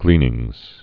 (glēnĭngz)